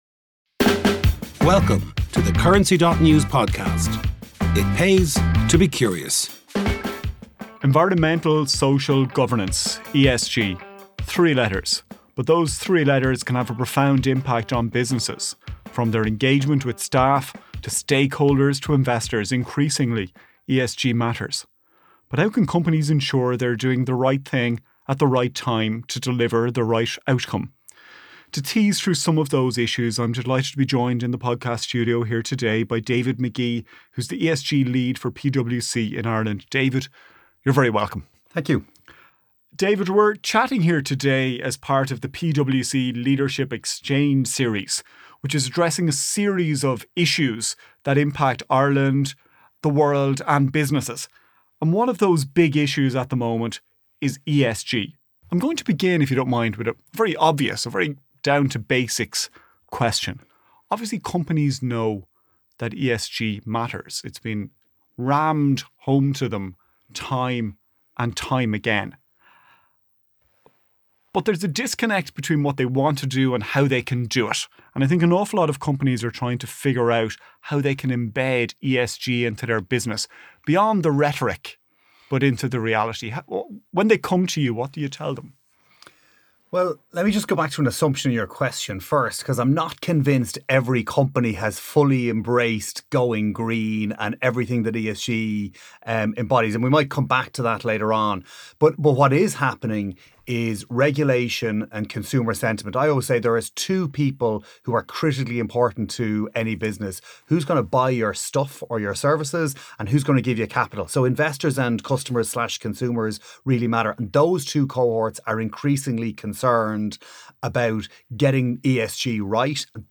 In a wide-ranging interview, he outlines how Agentic AI could transform workflows—but only for those willing to rethink governance, upskilling, and risk.